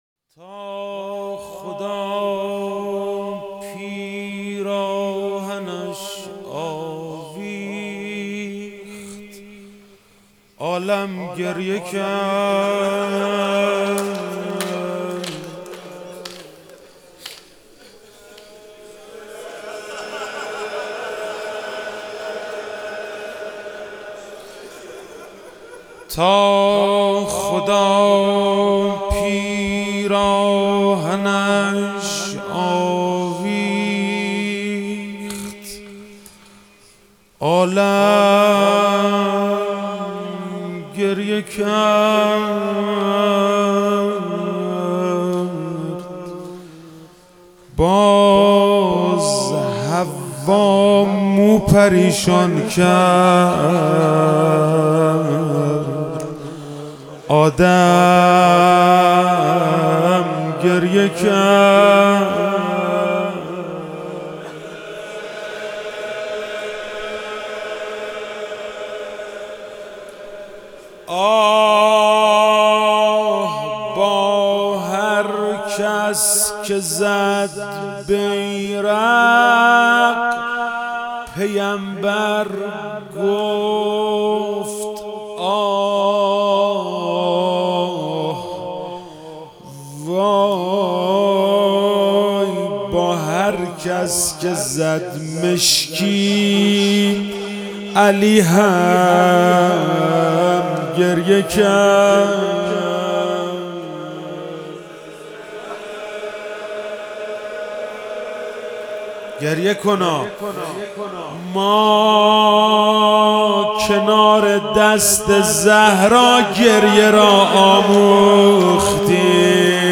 محرم 98 شب دوم - روضه - تا خدا پیراهنش آویخت